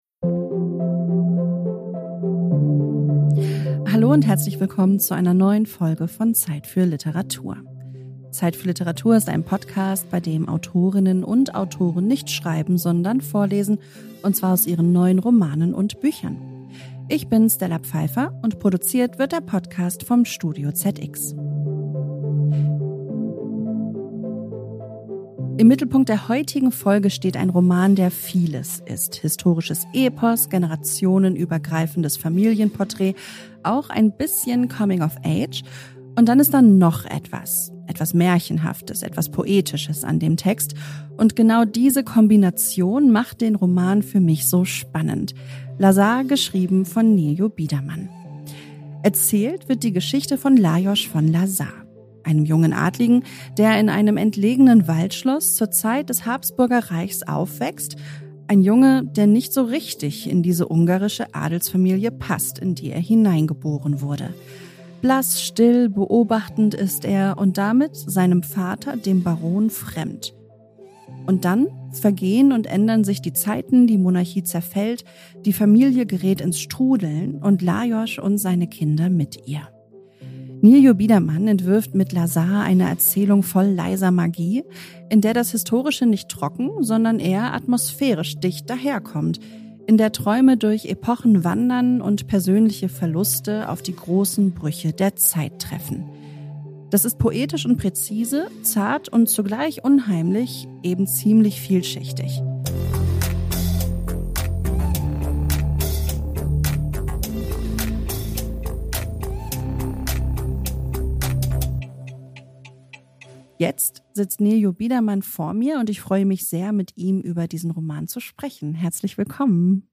ZEIT für Literatur – der neue Literaturpodcast, in dem Autorinnen und Autoren aus ihren neuen Romanen lesen und unsere Fragen beantworten. Produziert vom ZEIT Studio.